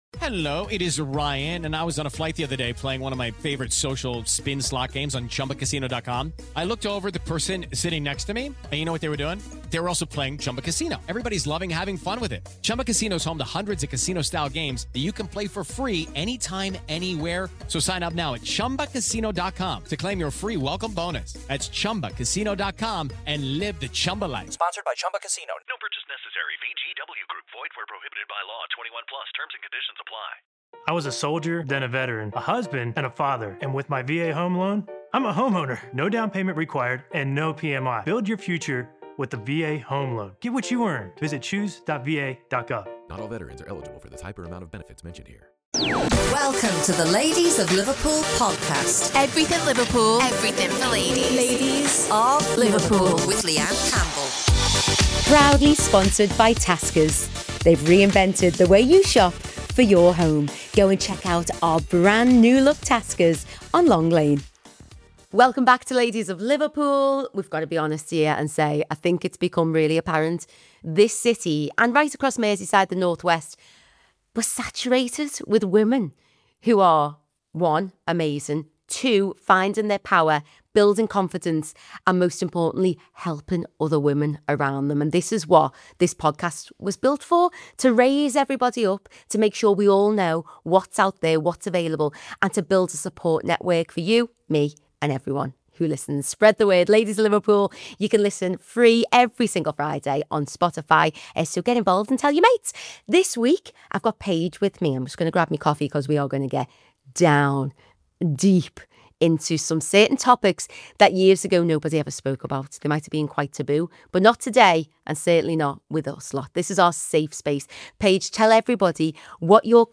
It’s an eye-opening conversation about health and awareness.